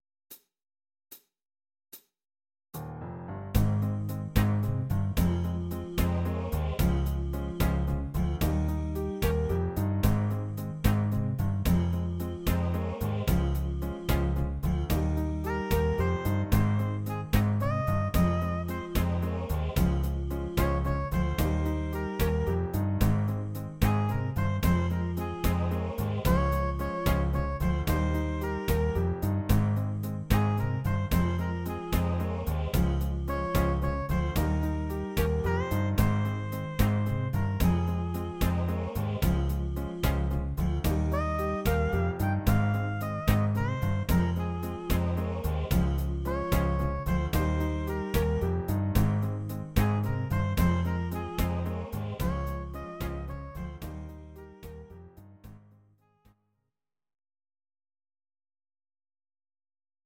Audio Recordings based on Midi-files
Our Suggestions, Pop, Oldies, 1950s